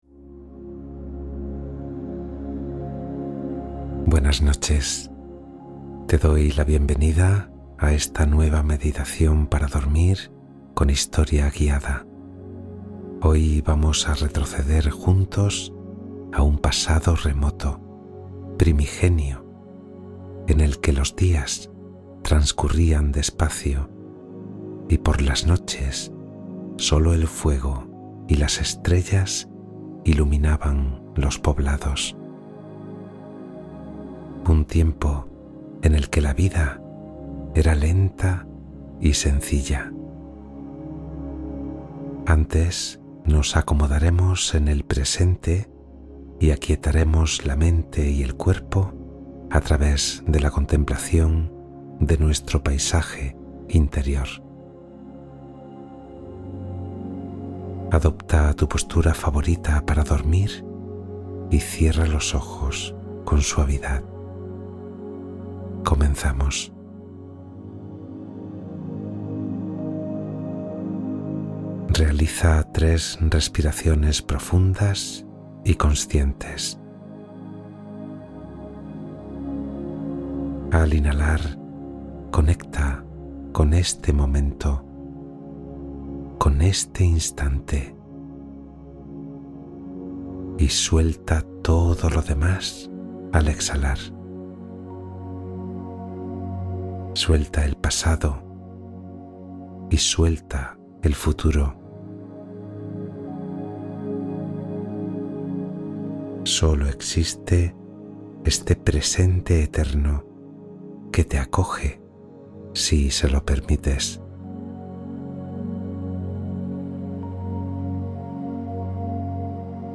Meditación relajante contra el insomnio | Paz mental asegurada